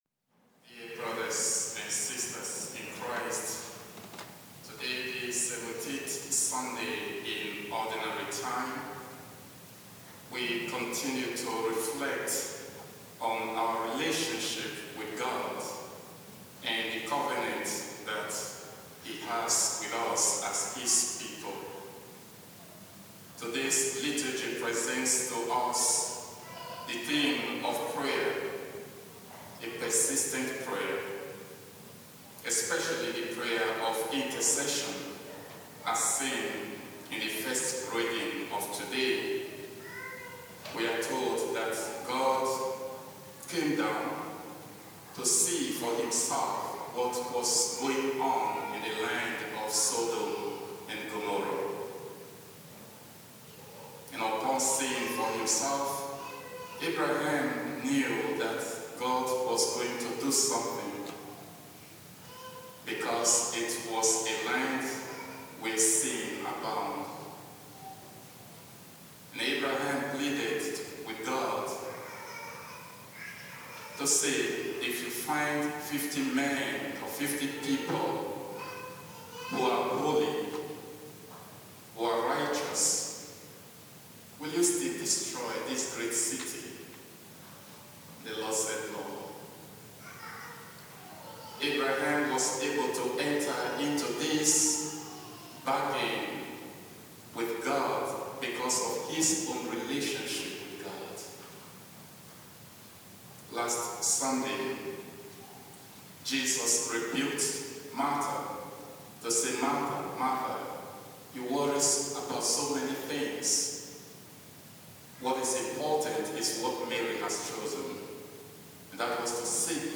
homily0727.mp3